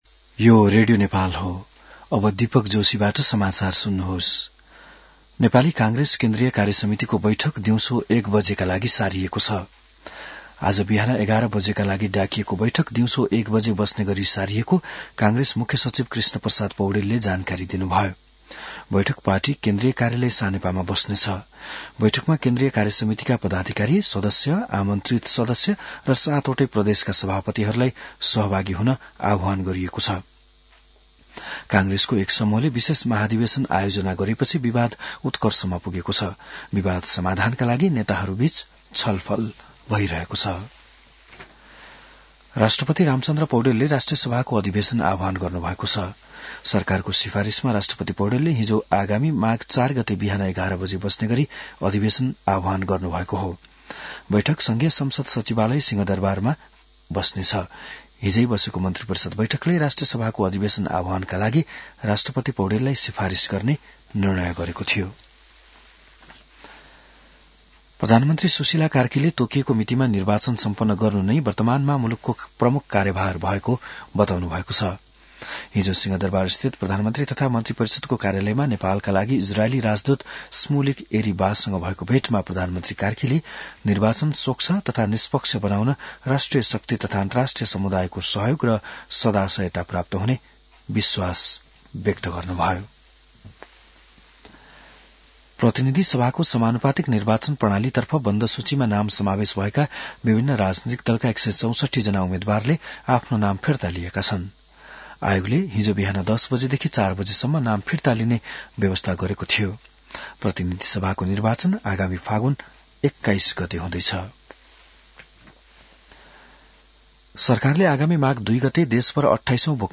बिहान १० बजेको नेपाली समाचार : २९ पुष , २०८२